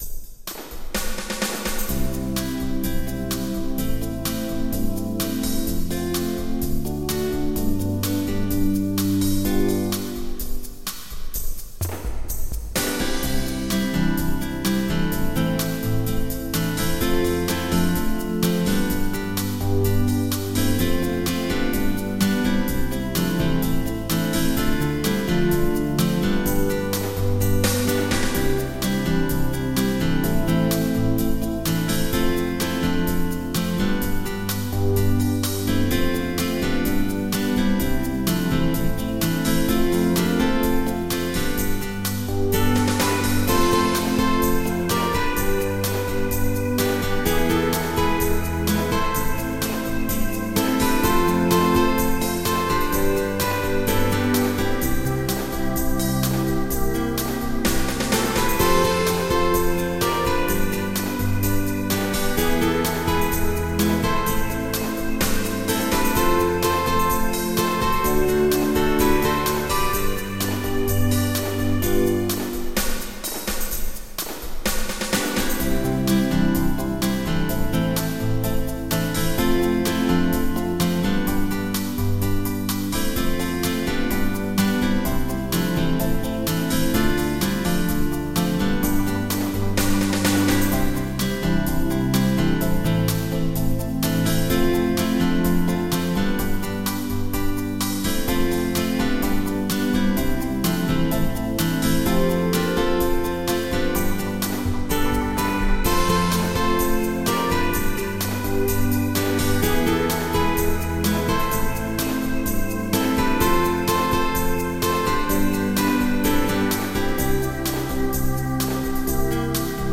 2005 Added mp3 recording from the midi file
Recording from MIDI